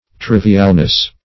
Trivialness \Triv"i*al*ness\, n.